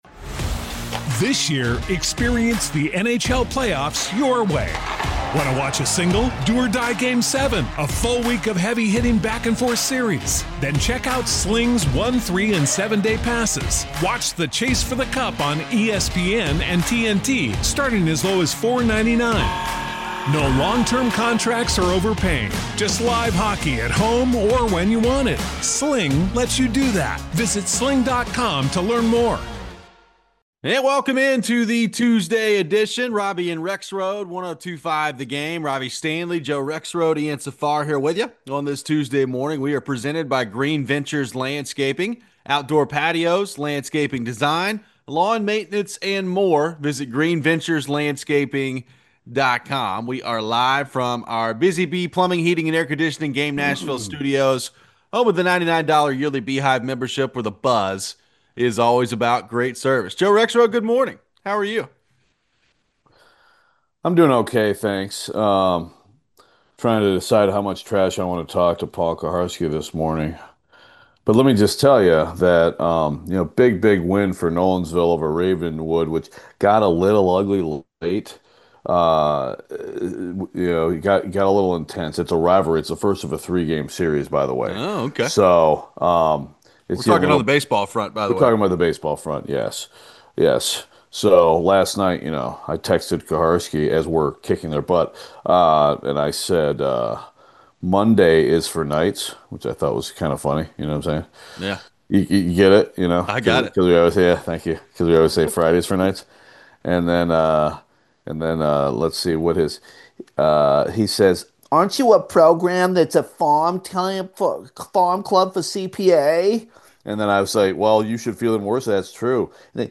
We hear some comments from Jonathan Marchessault about his future with the team. We take your phones.